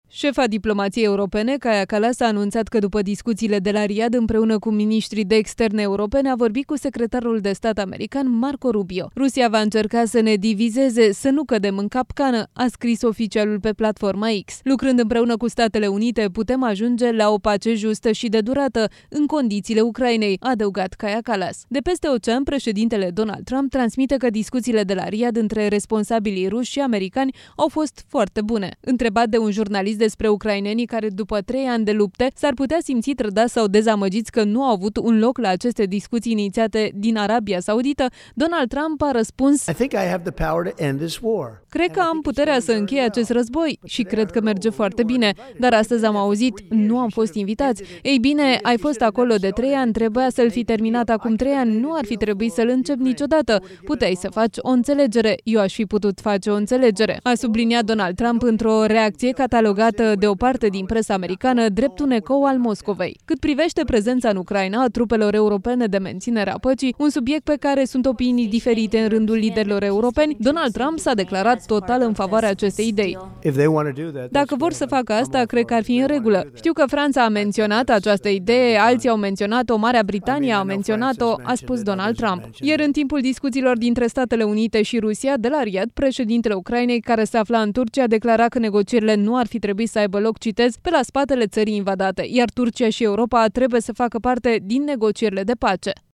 Într-o conferință de presă susținută în Florida, la reședința sa, președintele american Donald Trump s-a declarat „mai încrezător” în posibilitatea unui acord cu Moscova privind o posibilă încheiere a războiului din Ucraina, după discuţiile de la Riad.